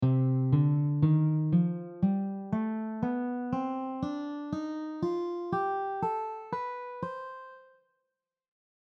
C melodic minor scaleCDEbFGAB
C melodic minor scale
C-melodic-minor-scale.mp3